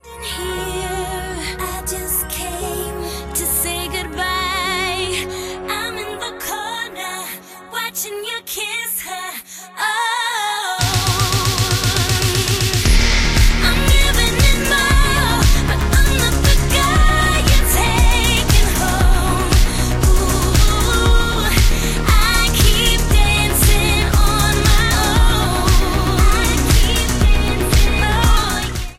Reduced quality: Yes